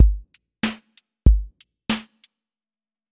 Boom bap pocket study with drifting warm haze, evolving motion, and soft body
QA Listening Test boom-bap Template: boom_bap_drums_a
Compose a deterministic boom bap pocket study with drifting warm haze, evolving motion, and soft body.
• voice_kick_808
• voice_snare_boom_bap
• voice_hat_rimshot